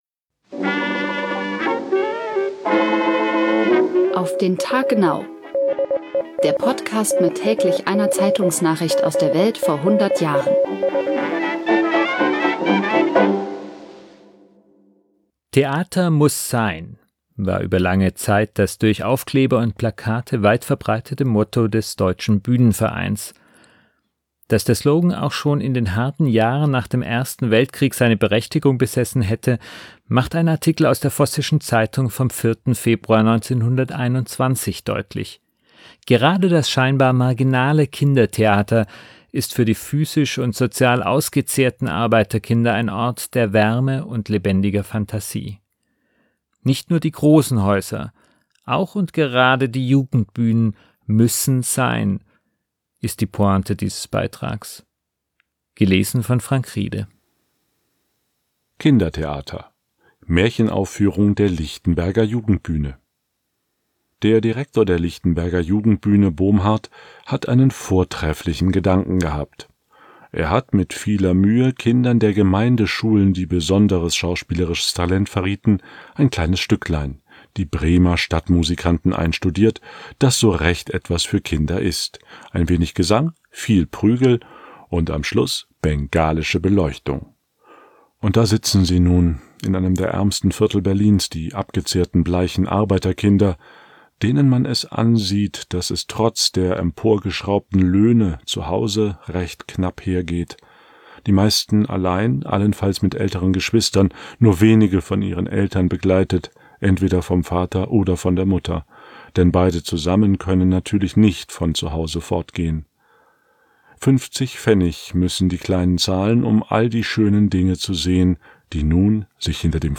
Gelesen